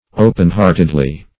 -- O"pen-heart`ed*ly , adv.